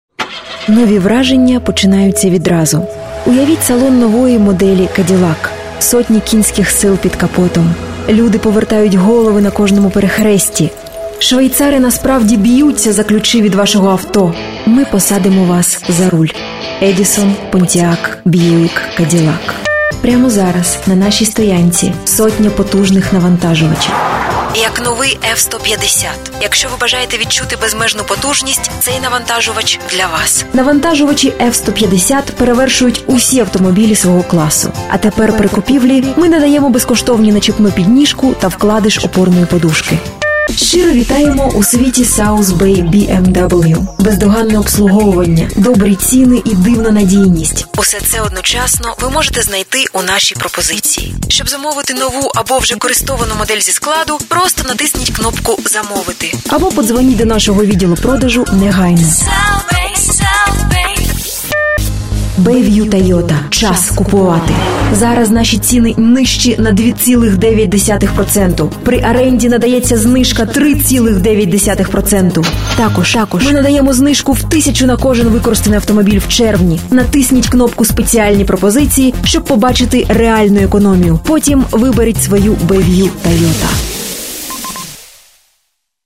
Russian Voice Over